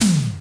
• 1980s Short Tom Drum Sound D# Key 27.wav
Royality free tom one shot tuned to the D# note. Loudest frequency: 3116Hz